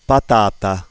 /pa'tata/ (potato), shown on the bottom of the rigth Figure, uttered in isolation by an Italian male speaker, the output of the Seneff model is quite effective in producing GSD spectra with a limited number of well defined spectral lines and also in tracking the dynamic modifications of speech.